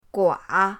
gua3.mp3